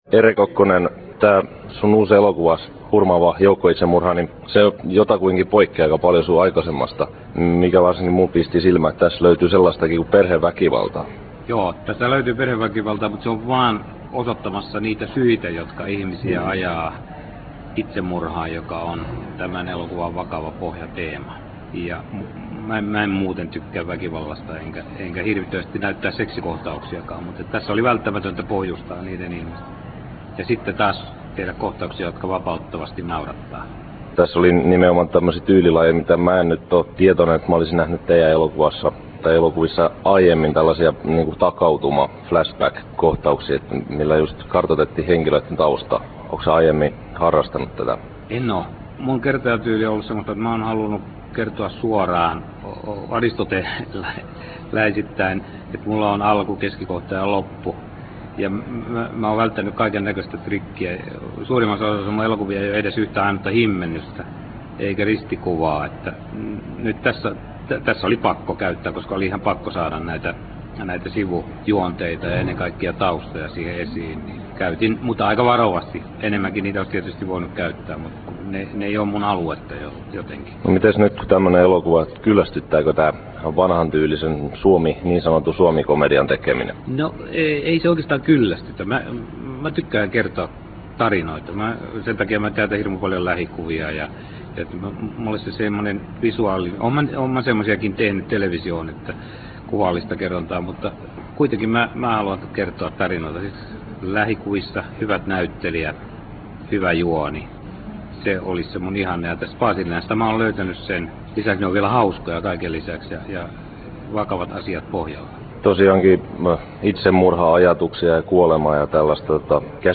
Nauhoitettu Turussa